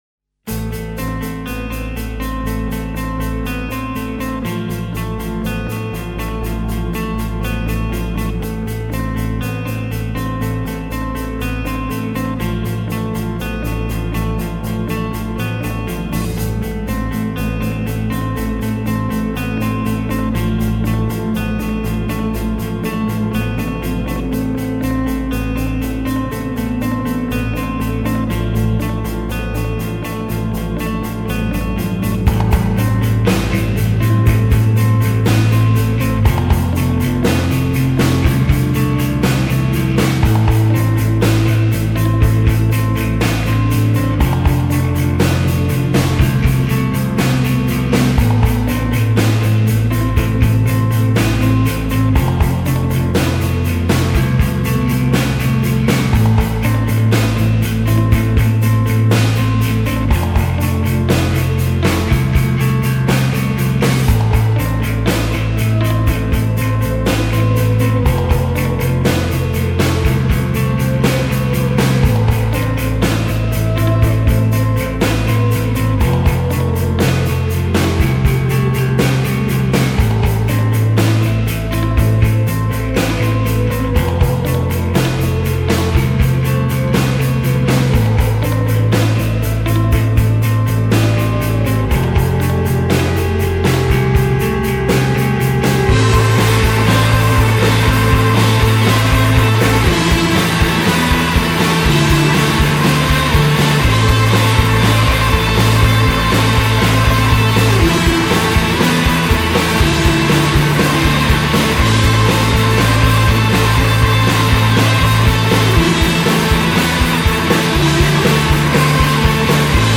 instrumental band
post-doom